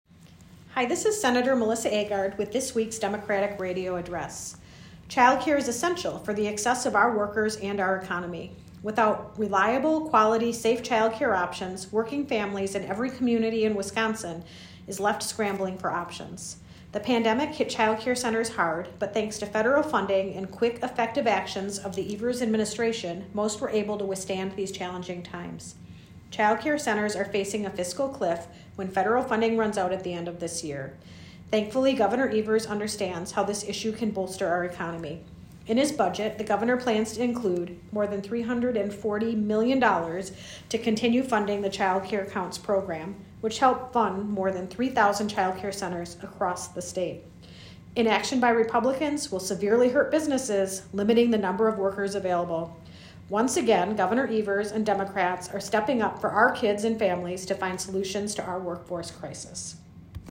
Weekly Dem radio address: Sen. Agard says child care is essential for the success of our workers and our economy - WisPolitics